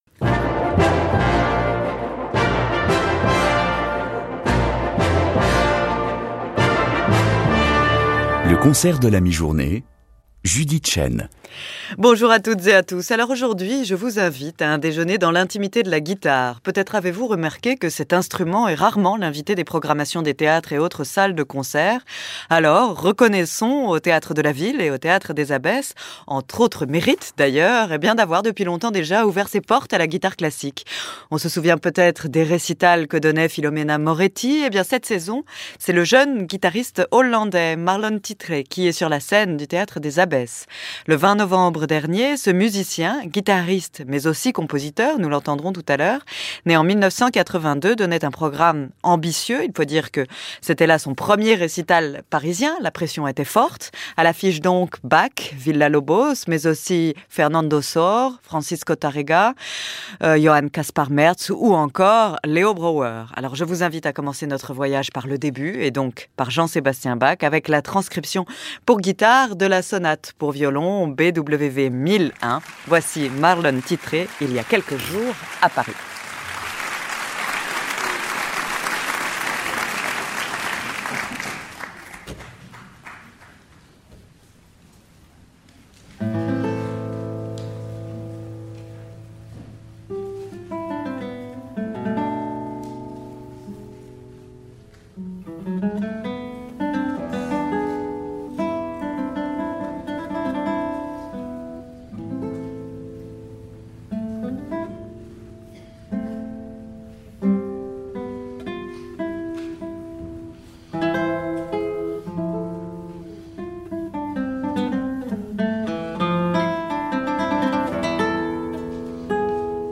Guitar Recital
Théåtre des Abbesses
Recorded live at Théåtre des Abbesses on November 20, 2010 by Radio France Musique it features a program of music by Bach, Mertz, Tárrega, Sor, Ponce, Villa-Lobos, Brouwe and Lauro.